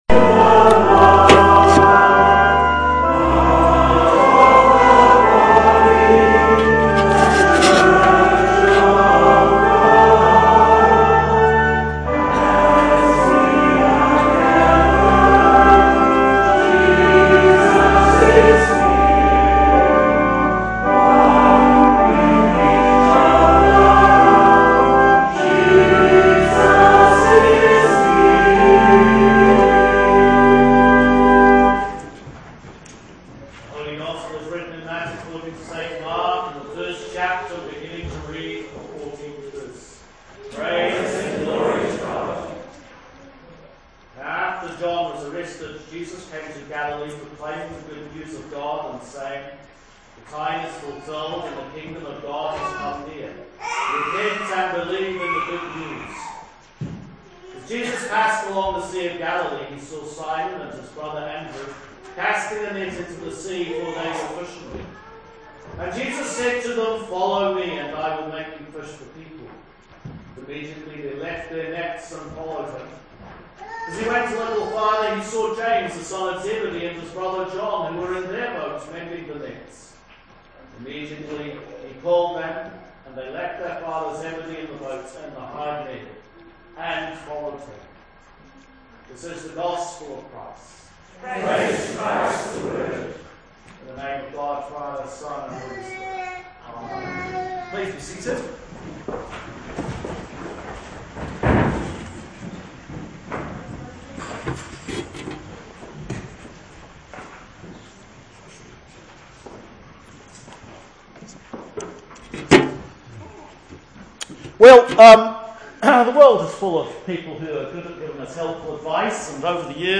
Sermon for 3rd Sunday after Epiphany